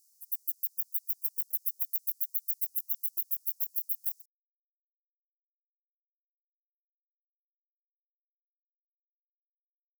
alluvial shieldback
10 s of calling song and waveform. Los Angeles County, California; 25.5°C. JCR04SF03.